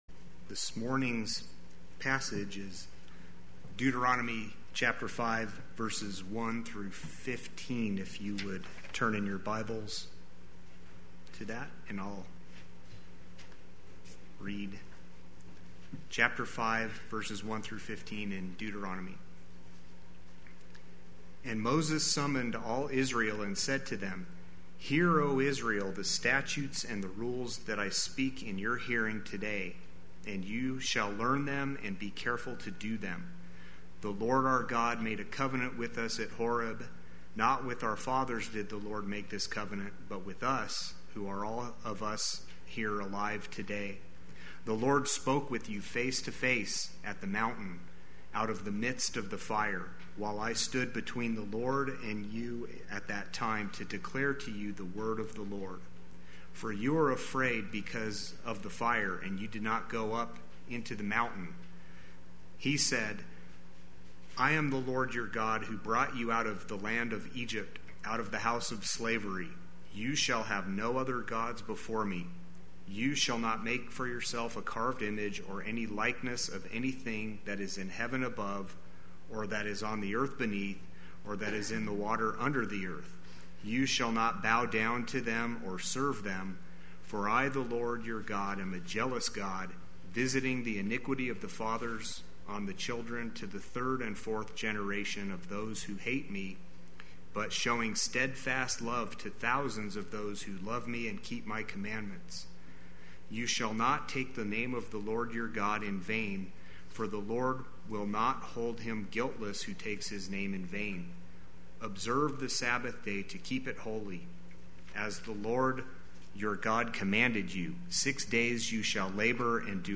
Play Sermon Get HCF Teaching Automatically.
Sunday Worship